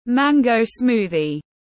Sinh tố xoài tiếng anh gọi là mango smoothie, phiên âm tiếng anh đọc là /ˈmæŋ.ɡəʊ ˈsmuːðiz/
Mango smoothie /ˈmæŋ.ɡəʊ ˈsmuːðiz/